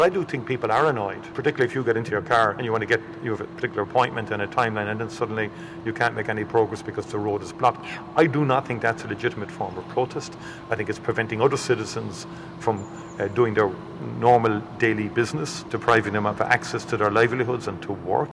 Micheál Martin says he believes many people are becoming increasingly irritated by the disruption: